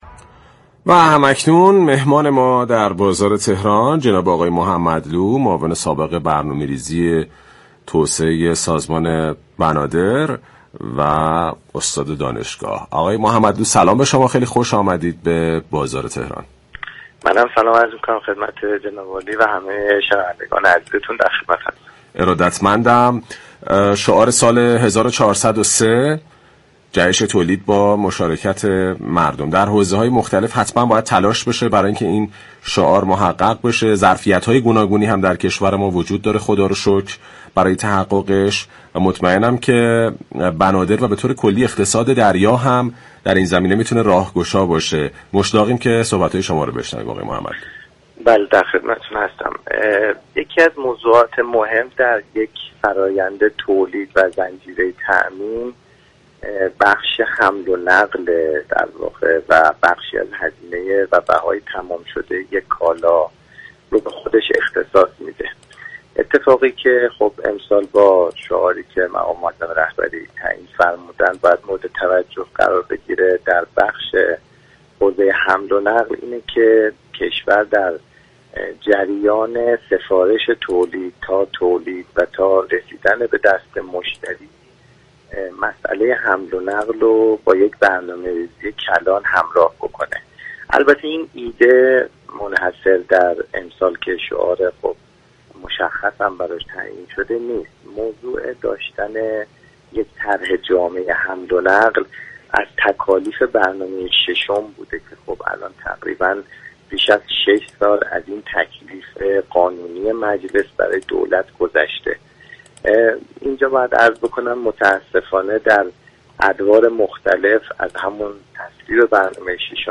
دولت باید جریان حمل و نقل را با یك برنامه ریزی كلان همراه كند به گزارش پایگاه اطلاع رسانی رادیو تهران، حسن بیك‌ محمدلو معاون سابق برنامه ریزی، توسعه مدیریت و بنادر و استاد دانشگاه در گفت و گو با «بازار تهران» اظهار داشت: در زنجیره تولید و تامین بخش حمل و نقل نقش بسیار مهمی دارد و بخشی از هزینه تمام شده كالا را به خود اختصاص می‌دهد.